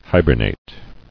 [hi·ber·nate]